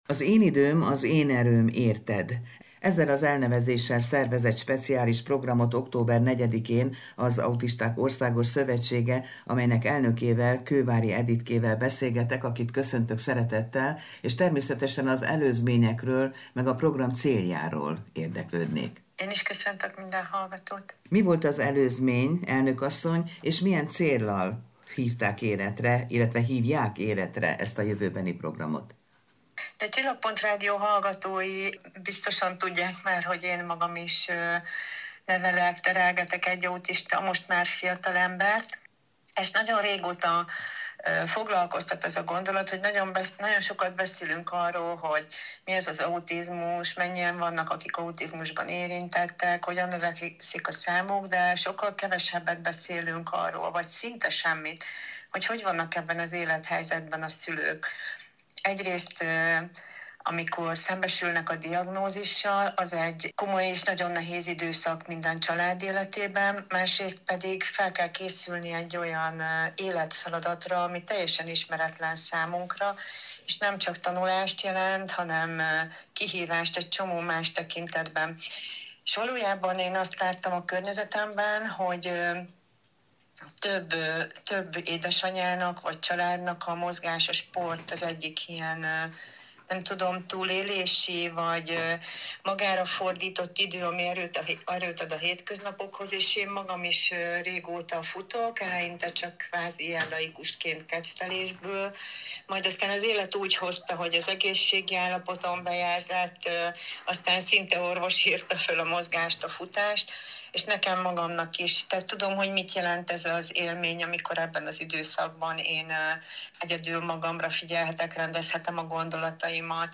Csillagpont-interju-2024-marcius.mp3